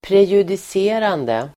Ladda ner uttalet
Uttal: [prejudis'e:rande] Definition: som kan tjänstgöra som prejudikat